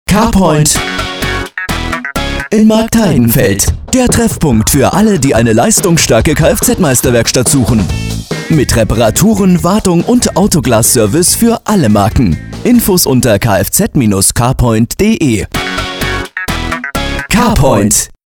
Carpoint-Radiospot
Carpoint-Radiospot.mp3